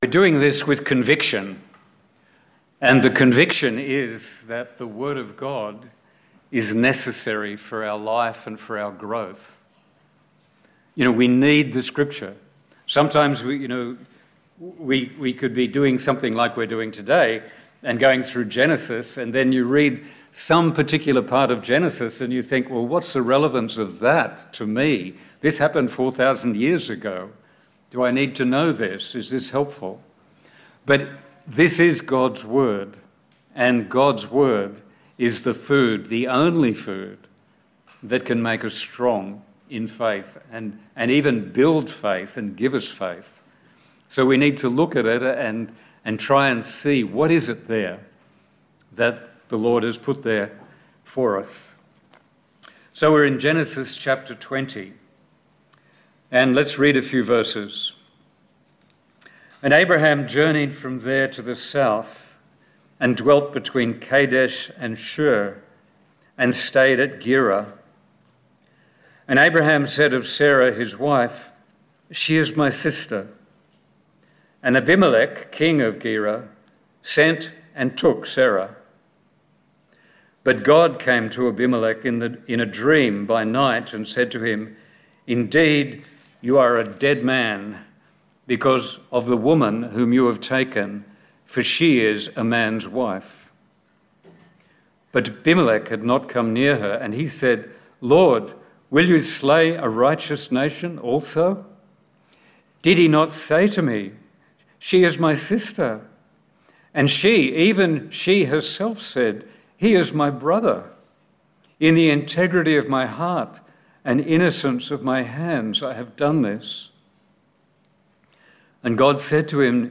The audio has been edited to remove an interruption as a result of the technical problems.